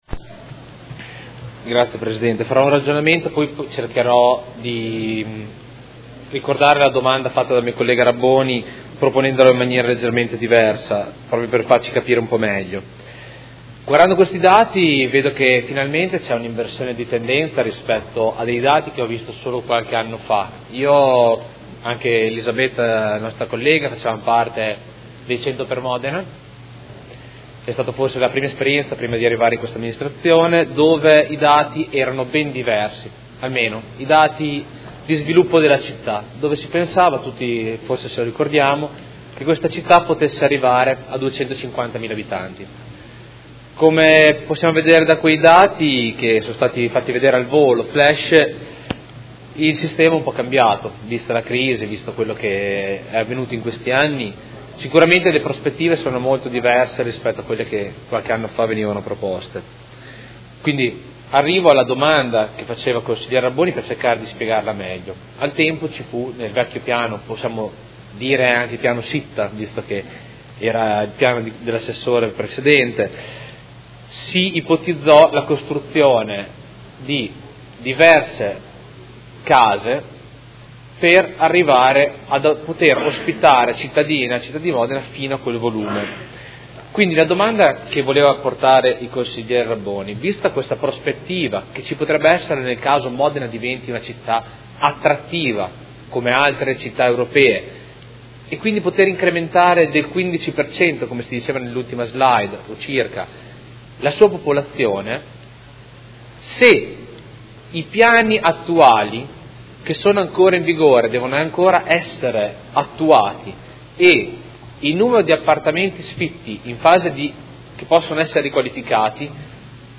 Seduta del 16/03/2017. Dibattito su 1° INCONTRO DEL PERCORSO VERSO IL NUOVO PIANO URBANISTICO
Audio Consiglio Comunale